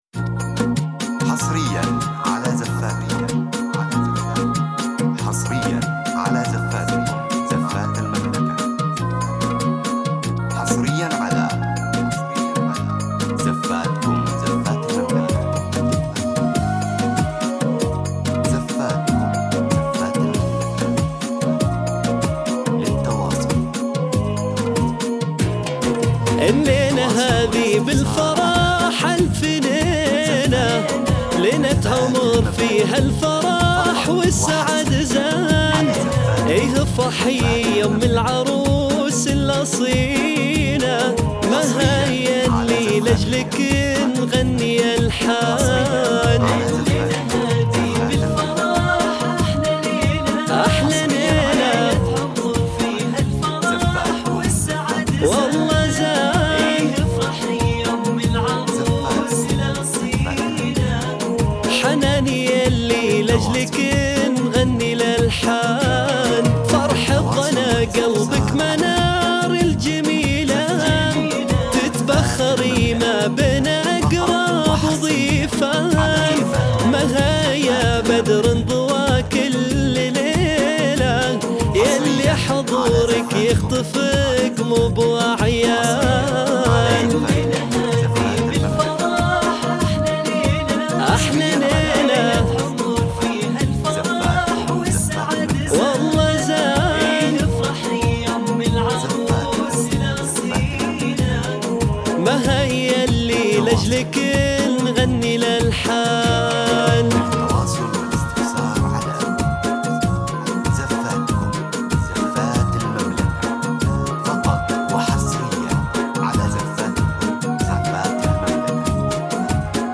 زفة إسلامية مميزة بدون موسيقى